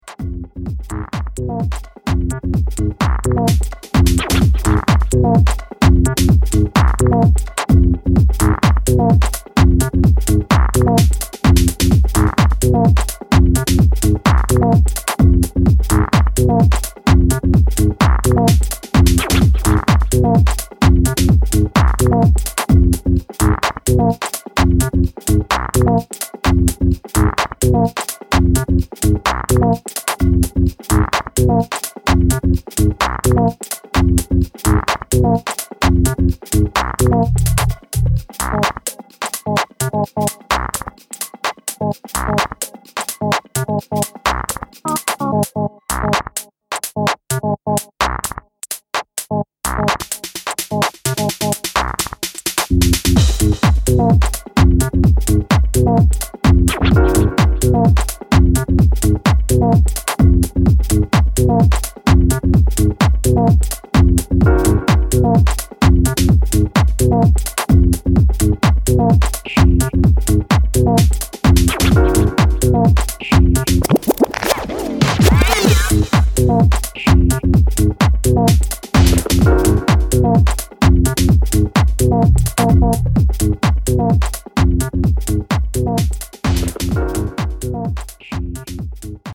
Trippy hardware minimal to influence the mind of your crowd.